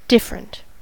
different: Wikimedia Commons US English Pronunciations
En-us-different.WAV